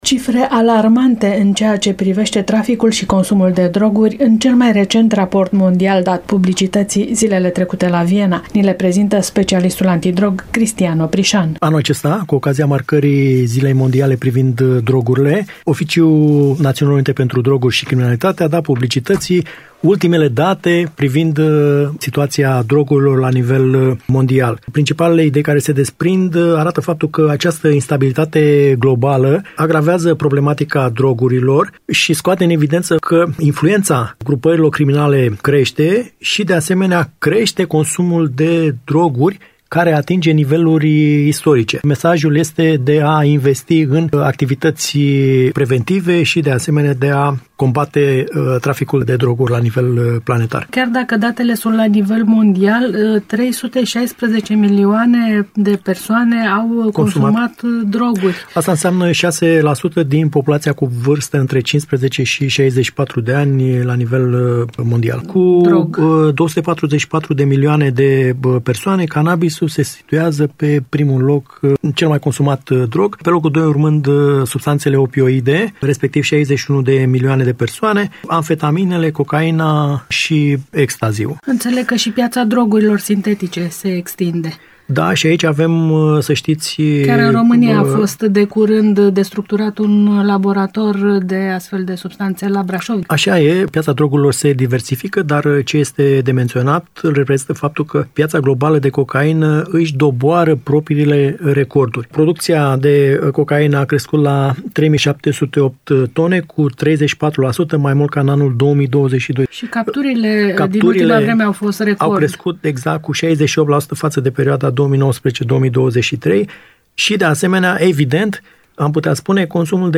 La nivel global, consumul de droguri a crescut. Interviu cu specialistul antidrog